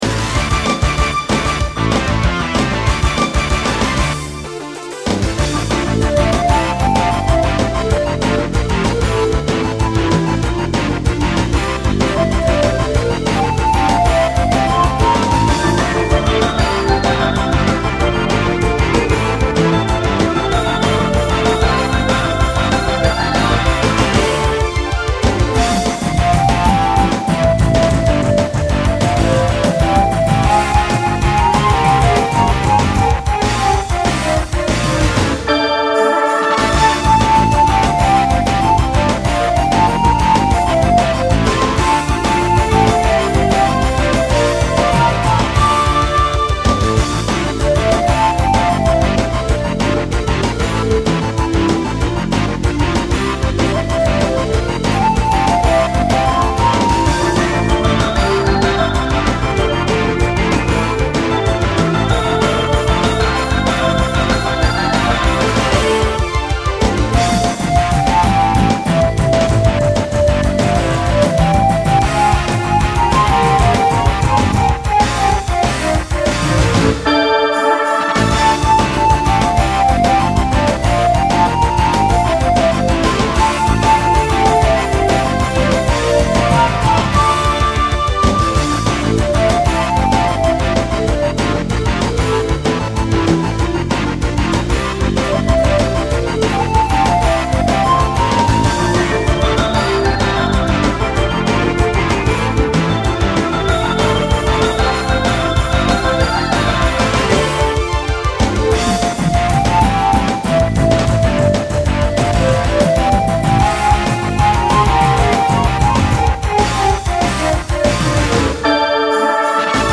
ORIJINAL MUSIC
ハウスっぽい(?)夕焼けの海岸沿いを疾走するようなイメージの曲。